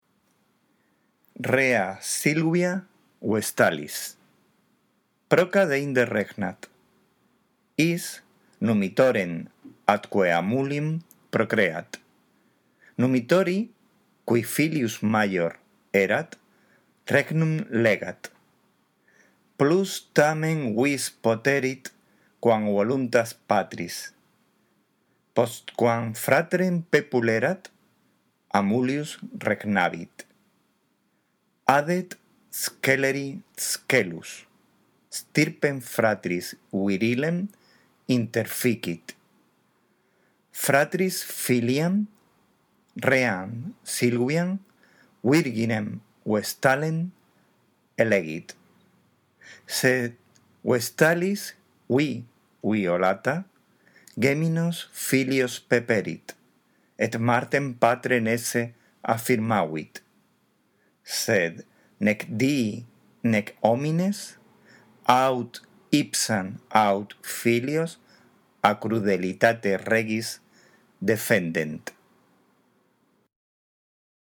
La audición de esta grabación te ayudará en la práctica de la lectura